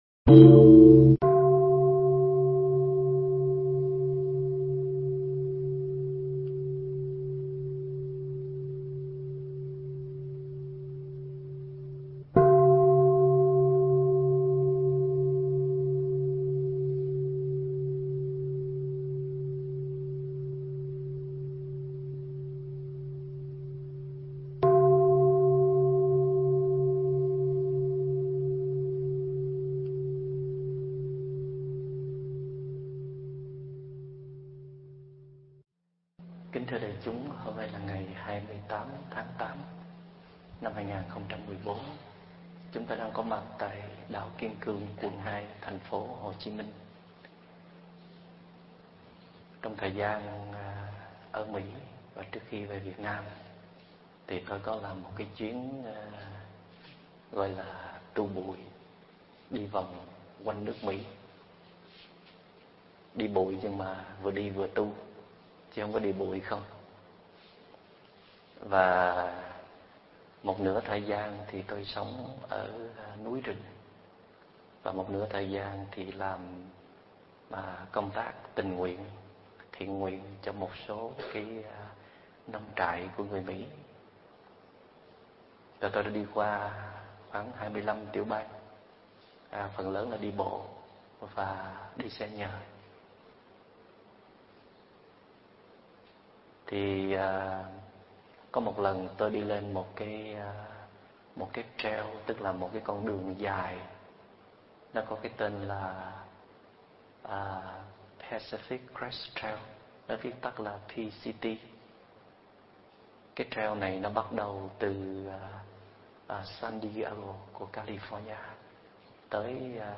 Pháp âm Nhìn Như Chính Đối Tượng Đang Hiện Ra